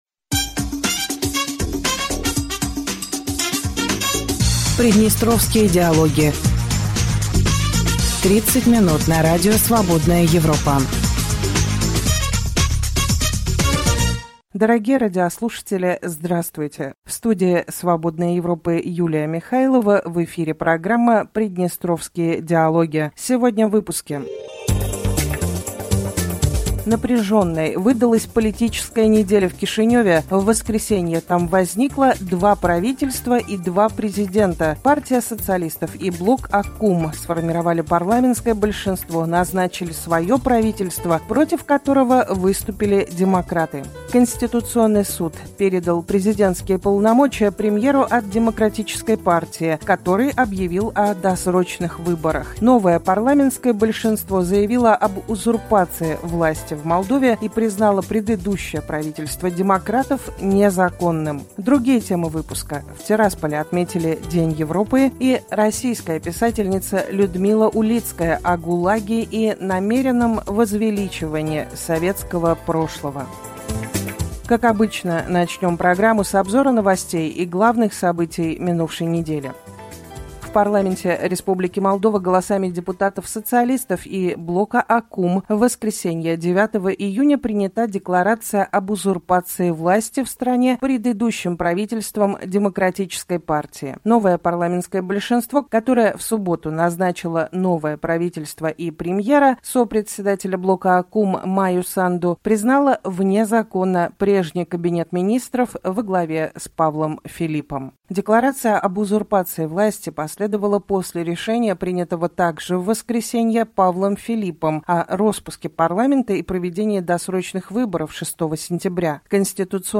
Дорогие радиослушатели, добрый день.